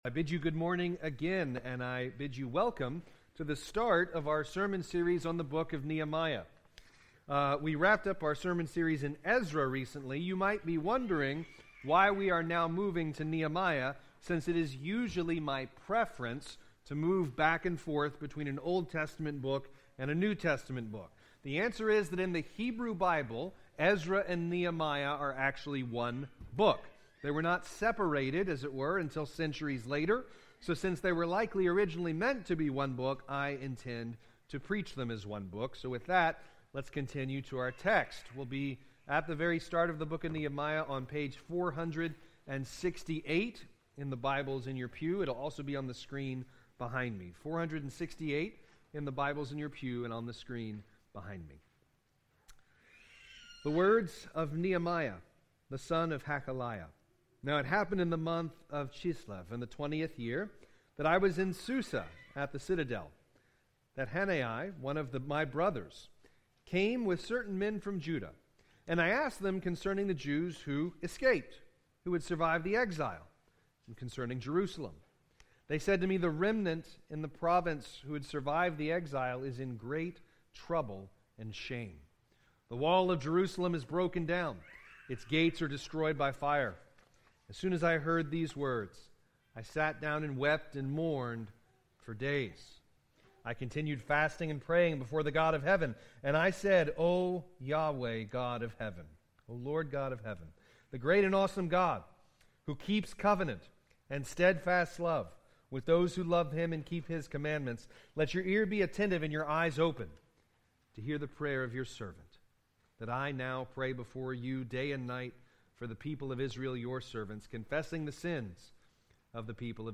Sermons by Grace Presbyterian Church - Alexandria, LA